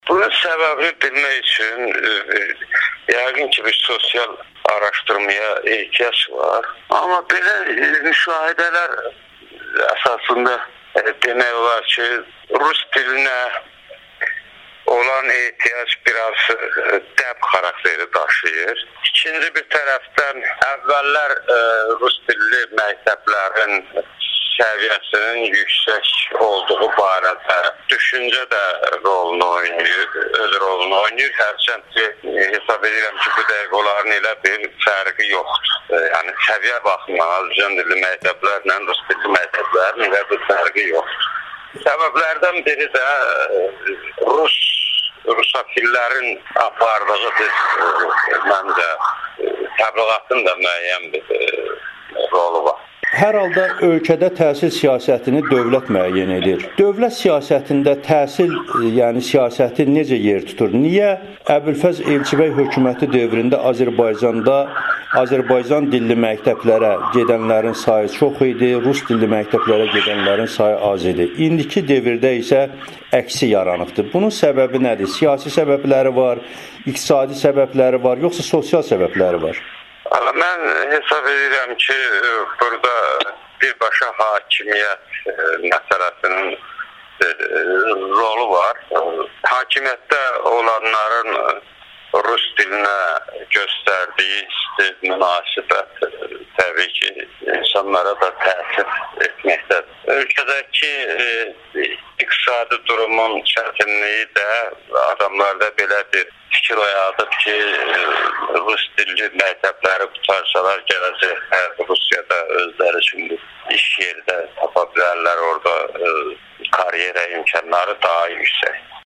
Keçmiş Tələbə Qəbulu üzrə Dövlət Komissiyasının sədri Vurğun Əyyub Amerikanın Səsinə müsahibəsi